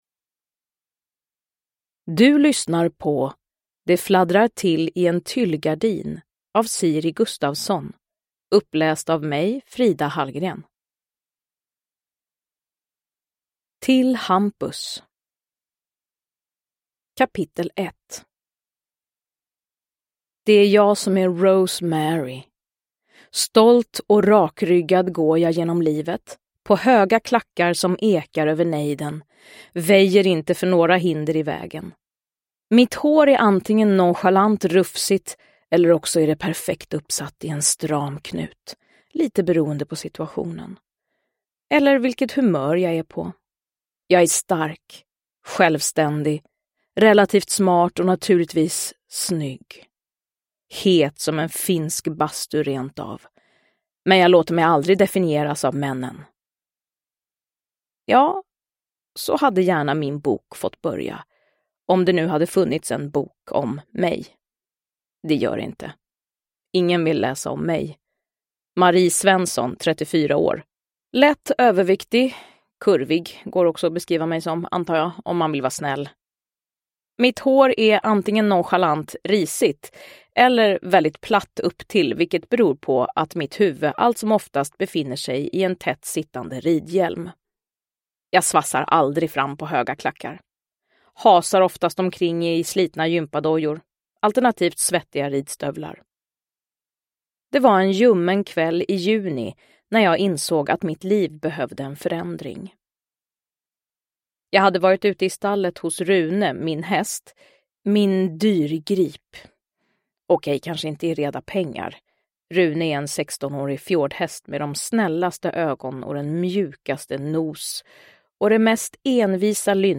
Det fladdrar till i en tyllgardin – Ljudbok – Laddas ner
Uppläsare: Frida Hallgren